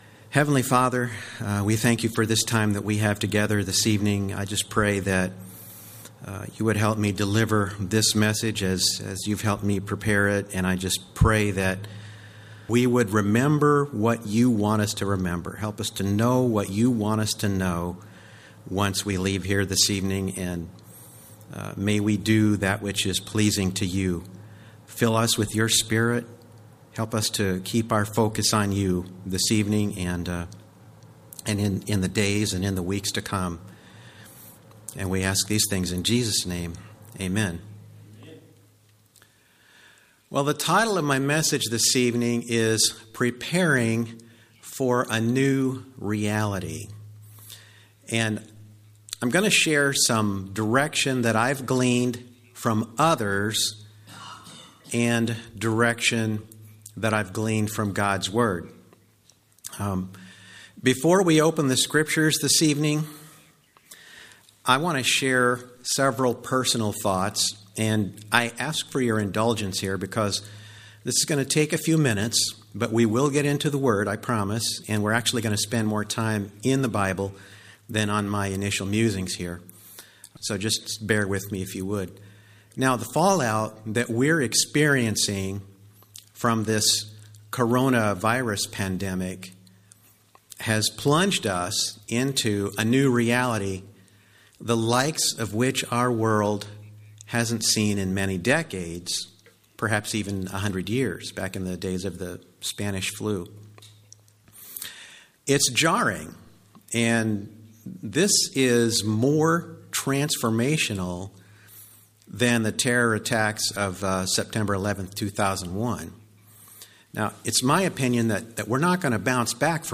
On Sunday evening, March 15, 2020, I had the privilege of delivering a message on preparing for a new reality at our church–Northwood Baptist Church, Columbia, MO.